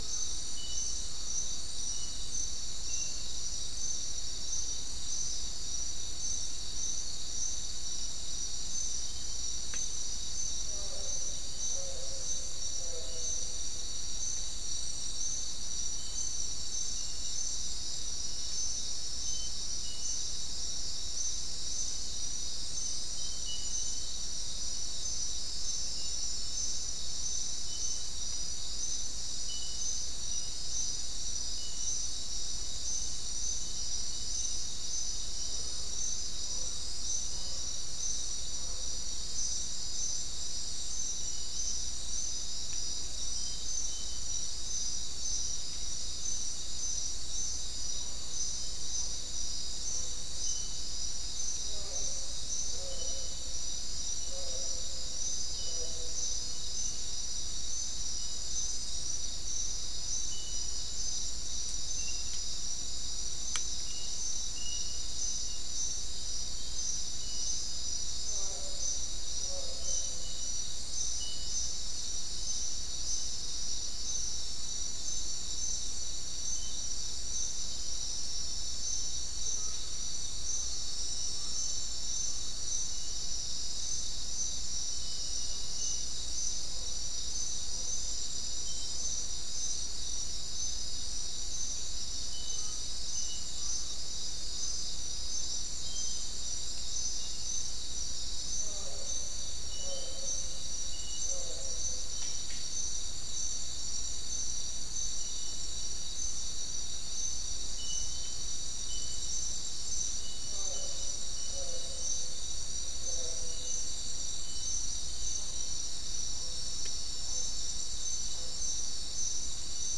Non-specimen recording: Soundscape Recording Location: South America: Guyana: Mill Site: 4
Recorder: SM3